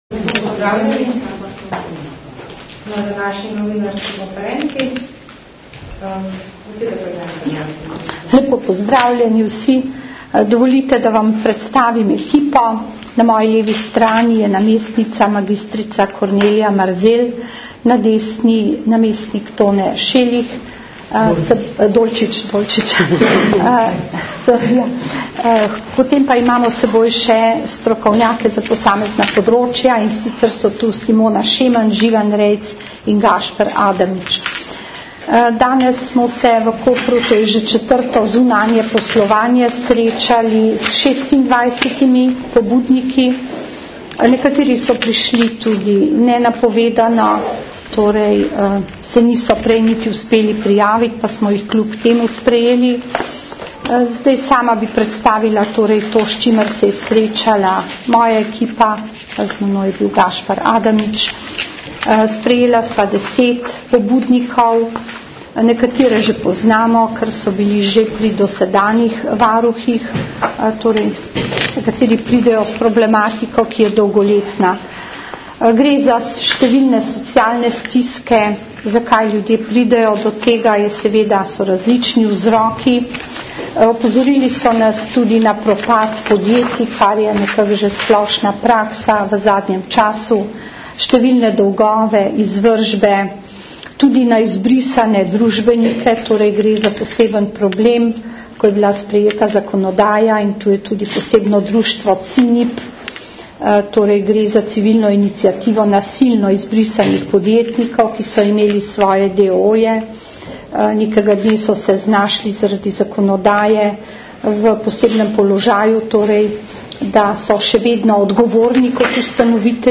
Po pogovorih je varuhinja na krajši novinarski konferenci predstavila glavne poudarke.